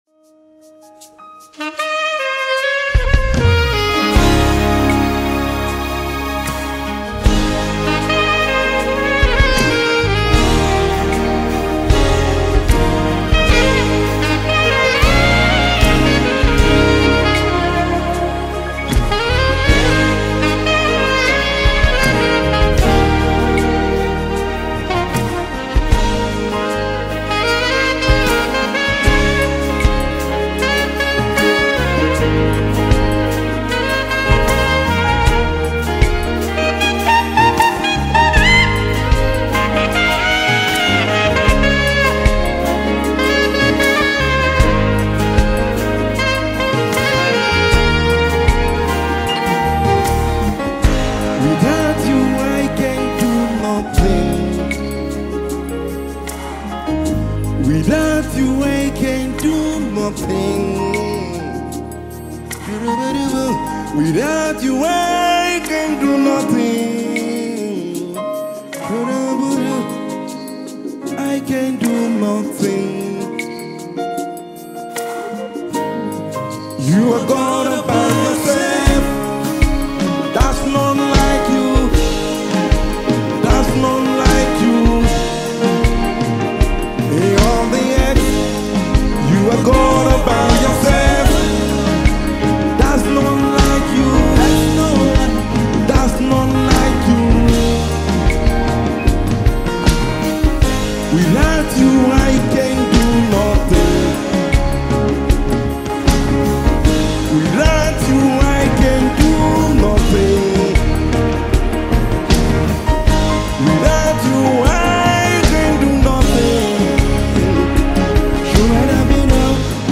February 8, 2025 Publisher 01 Gospel 0
songwriter and saxophonist.